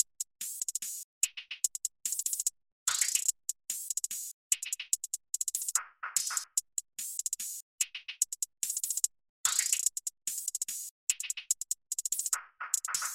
标签： 146 bpm Trap Loops Drum Loops 2.21 MB wav Key : Unknown
声道立体声